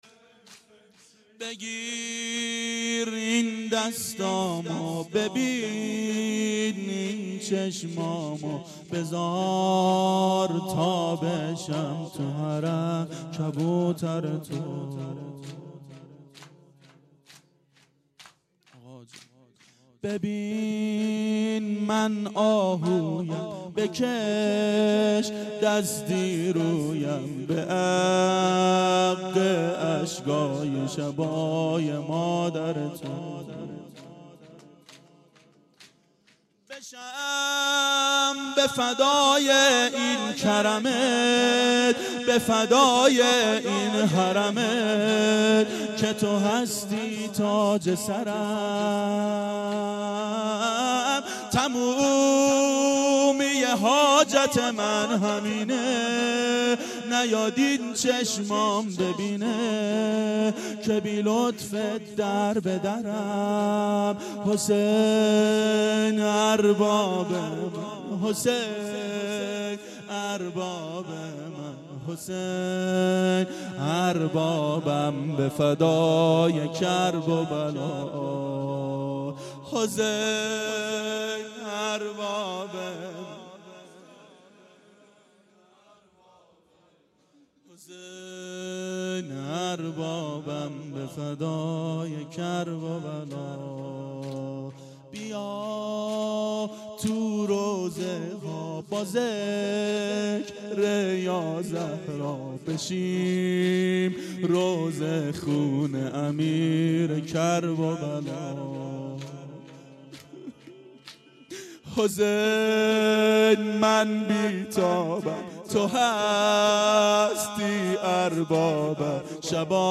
شب اول محرم 92 هیأت عاشقان اباالفضل علیه السلام منارجنبان